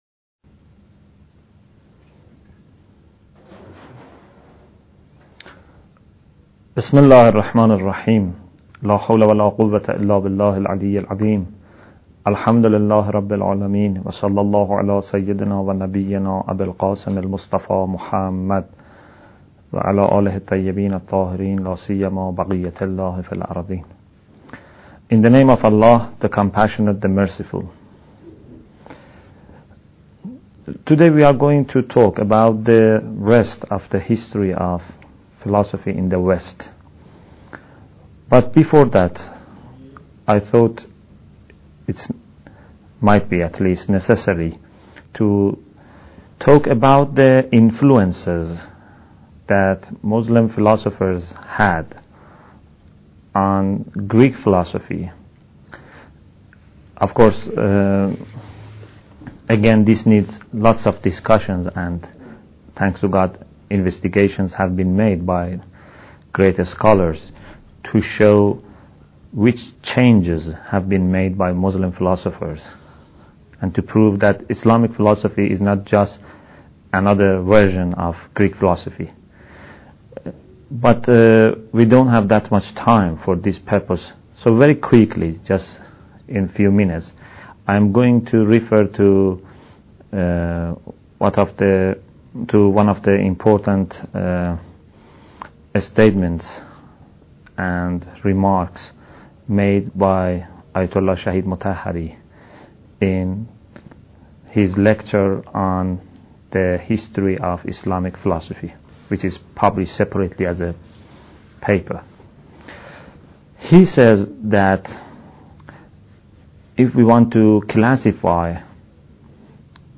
Bidayat Al Hikmah Lecture 4